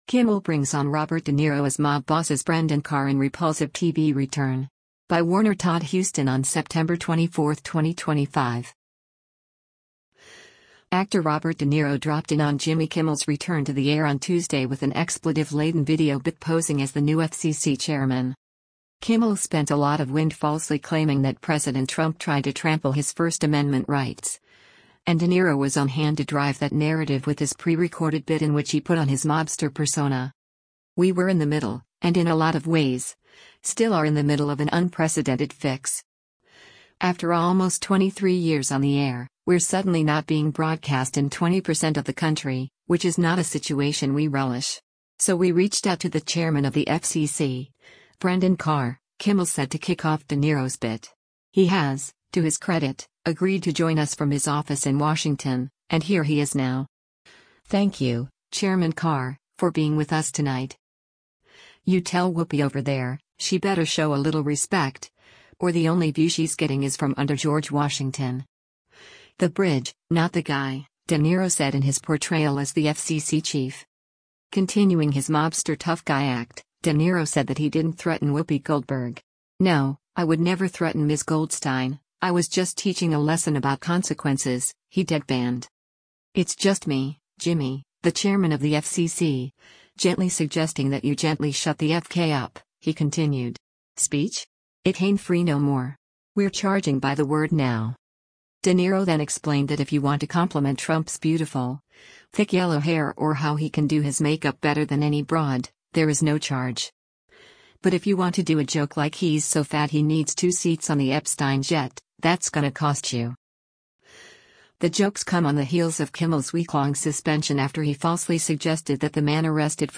Actor Robert De Niro dropped in on Jimmy Kimmel’s return to the air on Tuesday with an expletive-laden video bit posing as the “new” FCC chairman.
Kimmel spent a lot of wind falsely claiming that President Trump tried to trample his First Amendment rights, and De Niro was on hand to drive that narrative with his prerecorded bit in which he put on his mobster persona.